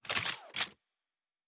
Weapon 09 Reload 1.wav